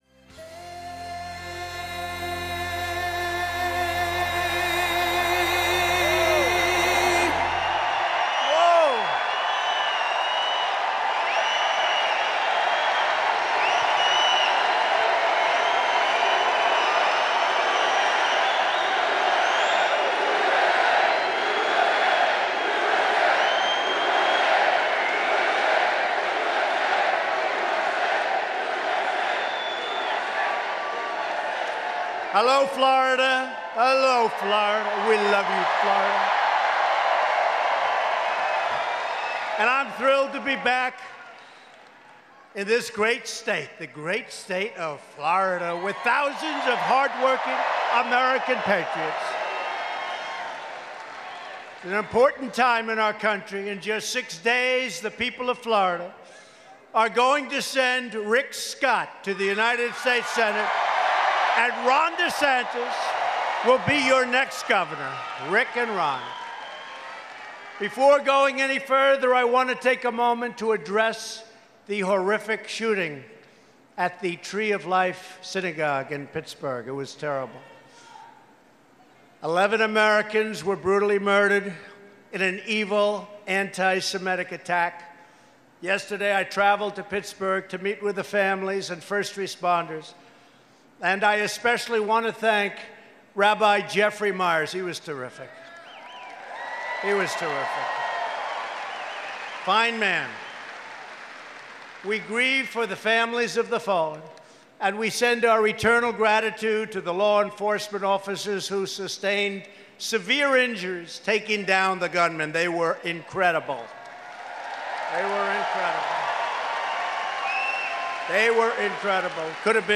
U.S. President Donald Trump speaks at a Republican campaign rally in Estero, FL